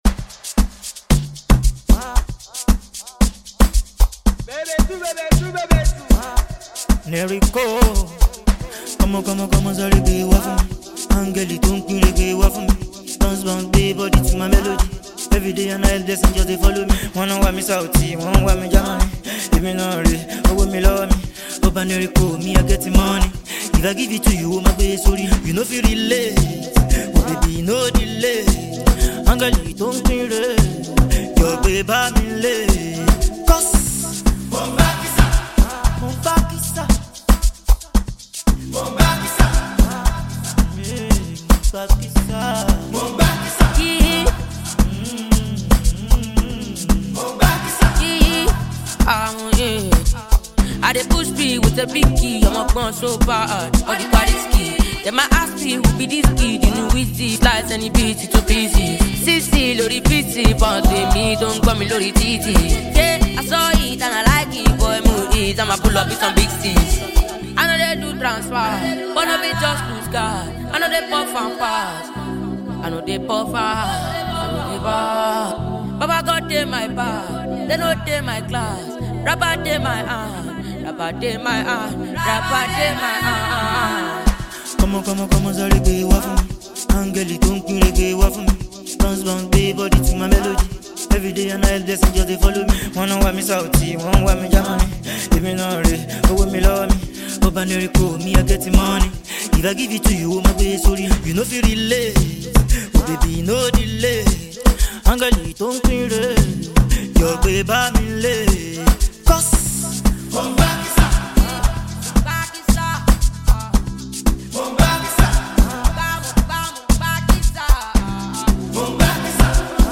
Afrobeats Released